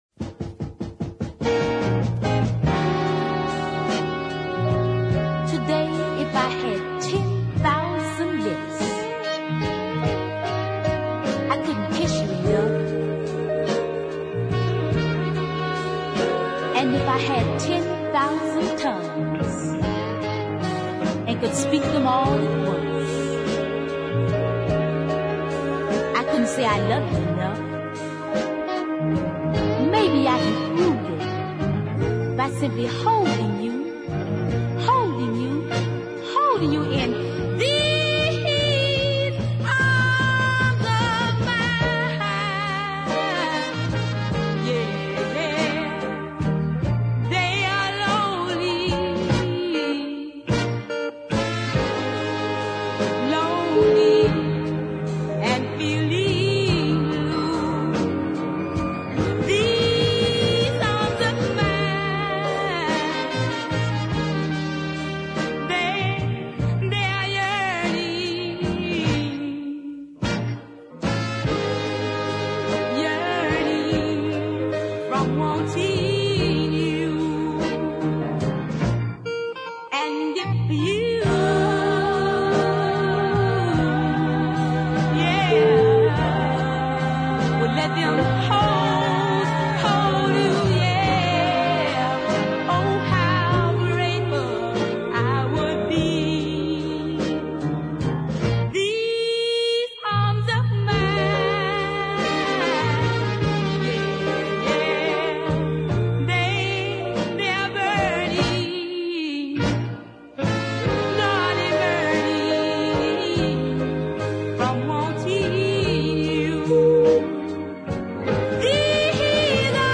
haunting blues ballad